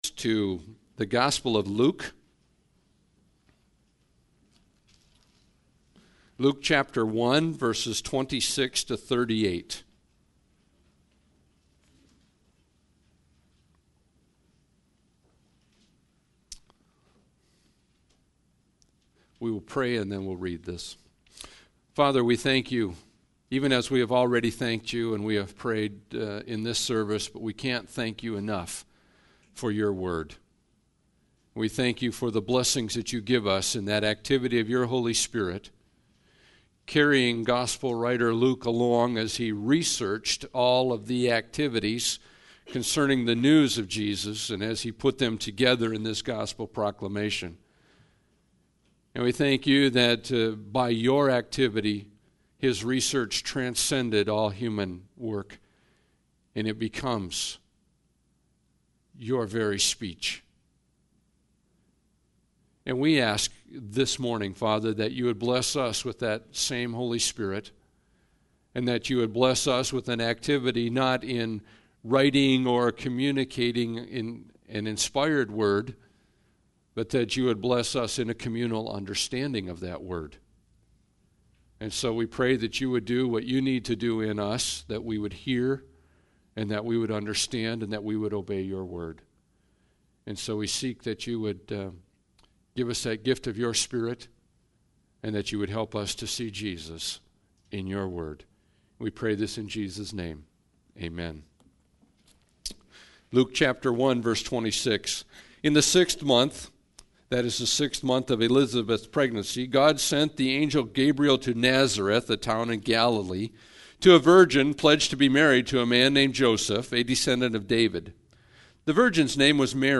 Preparing For Christ – Christmas Eve Service